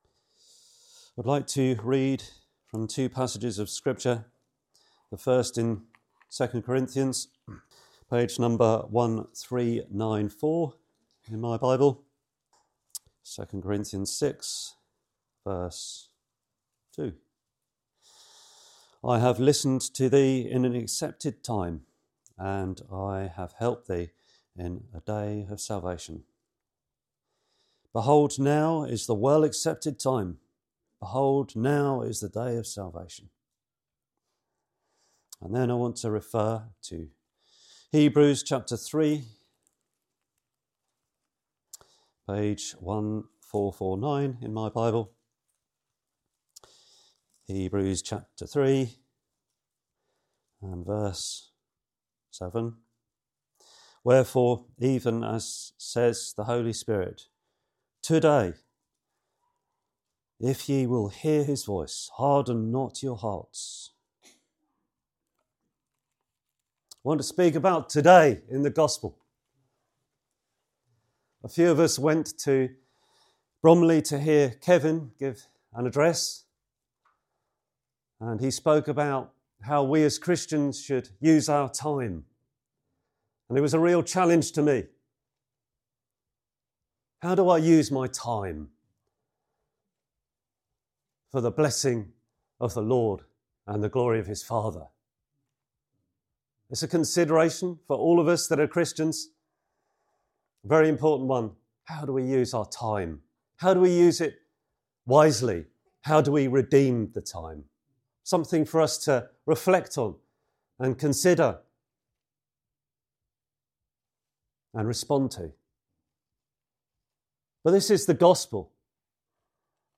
This Gospel preaching urges you not to delay—hear God’s voice today and receive His saving grace through Jesus Christ, who gave His life on the cross, bore our sins, and rose again to bring forgiveness and new life.